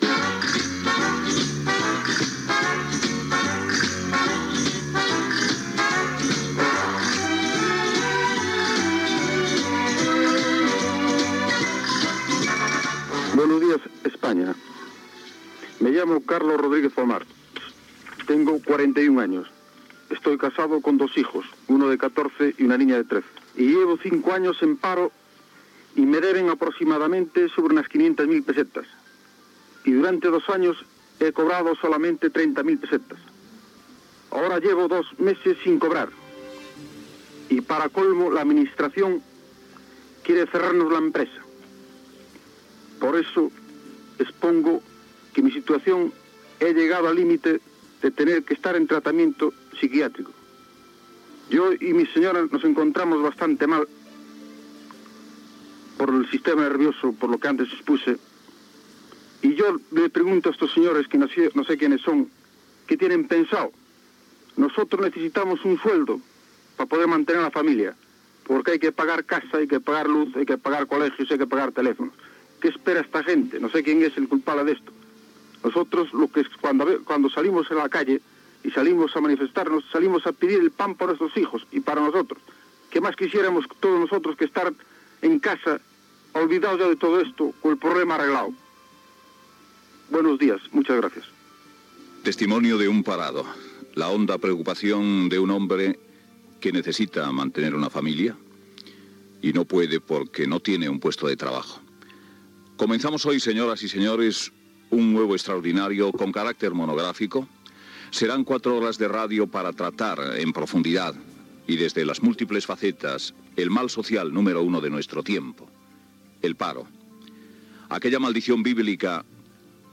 Testimoni d'un aturat.
Persones presents a l'estudi i trucades telefòniques.
Intervenció del ministre de Treball Joaquín Almunia.